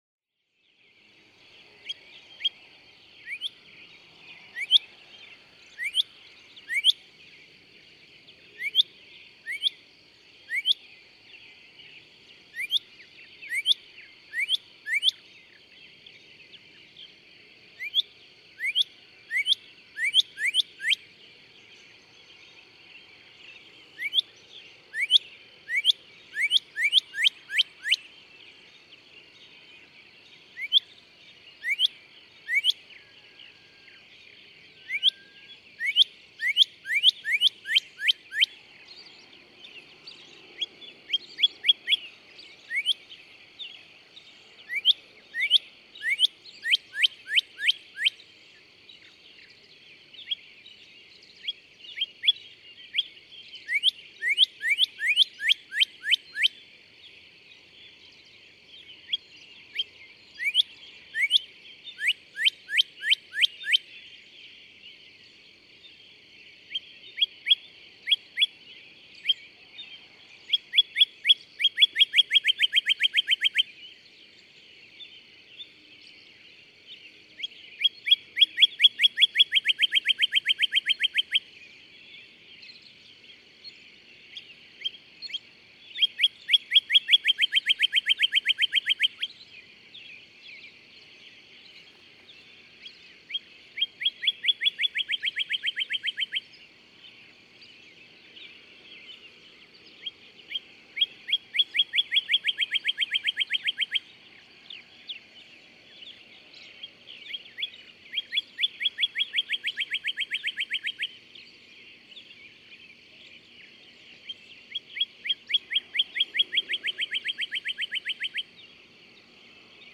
Northern cardinal
More songs, with approaching train.
Sebree, Kentucky.